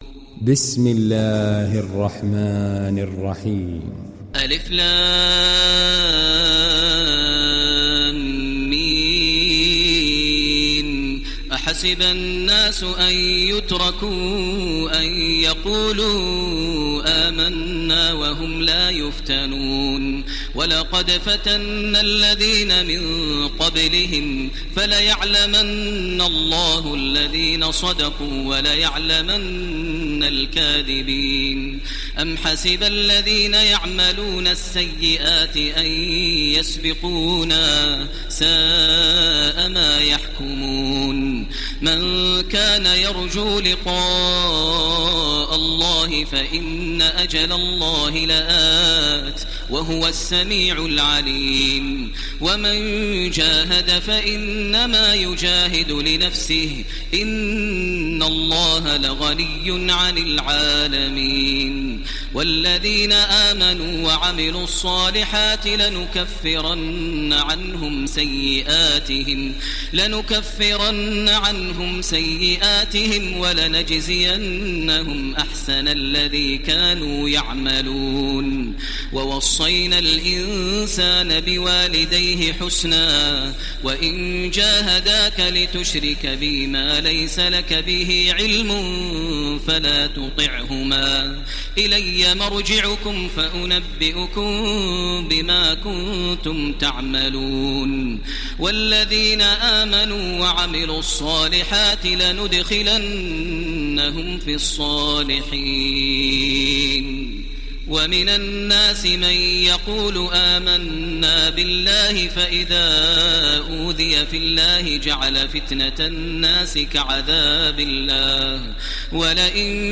دانلود سوره العنكبوت تراويح الحرم المكي 1430